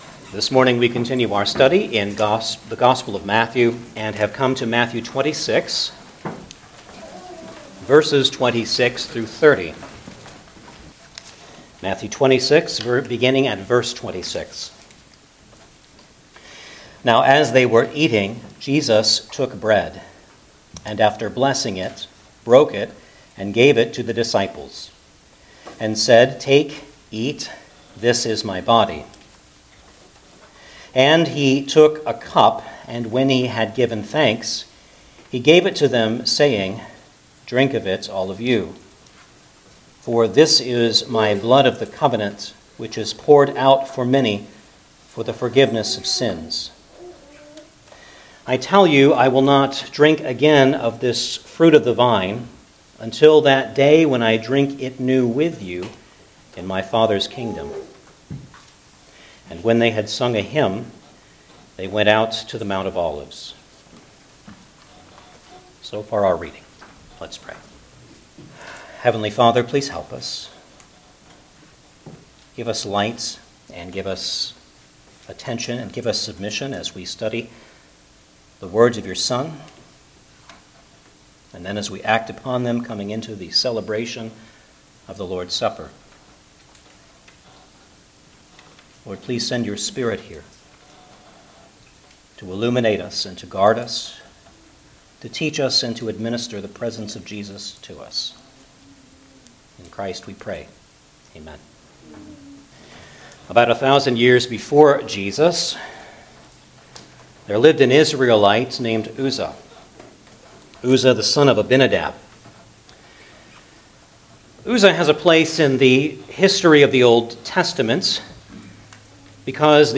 Matthew 26:26-30 Service Type: Sunday Morning Service Download the order of worship here .